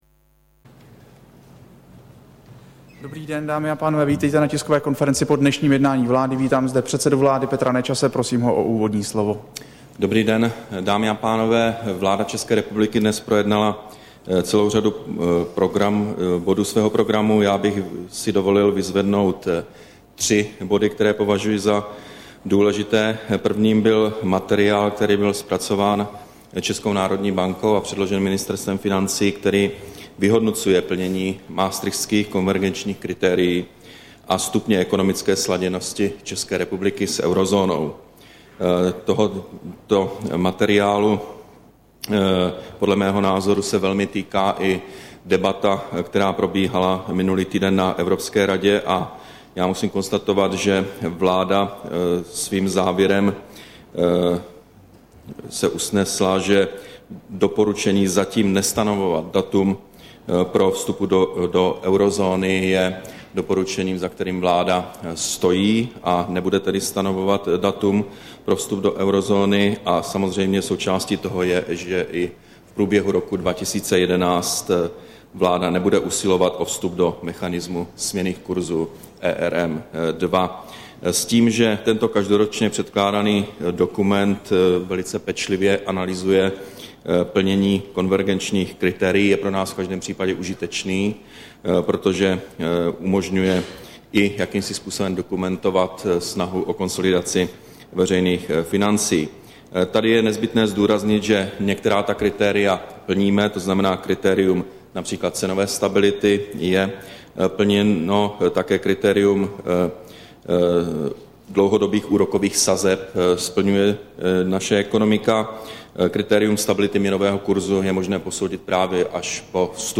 Tisková konference po jednání vlády, 22. prosinec 2010